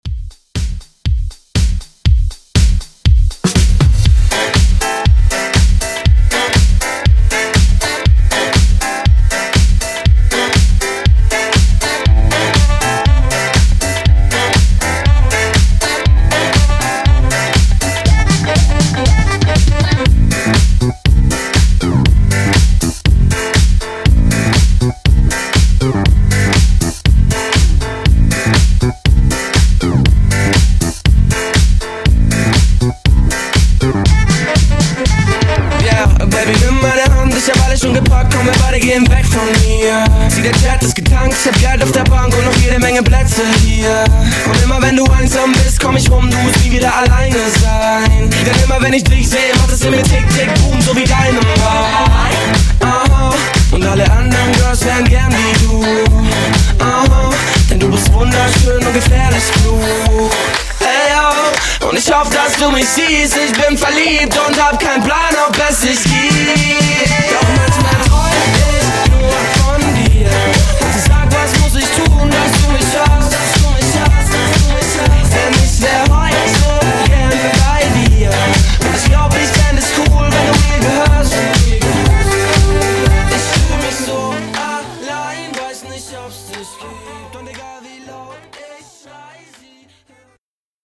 Genre: COUNTRY
Clean BPM: 100 Time